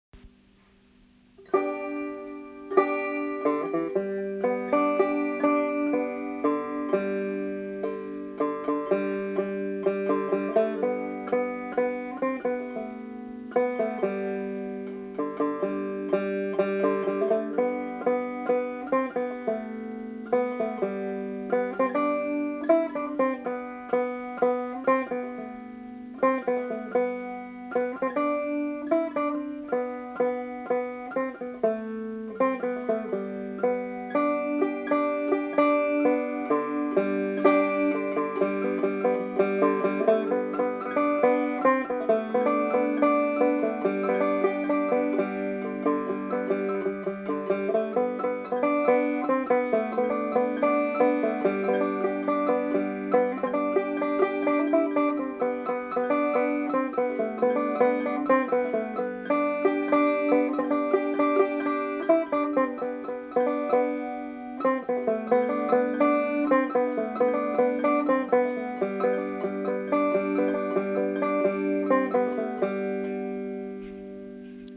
Played on my Bishline banjo
Recording-send-the-light-banjo.mp3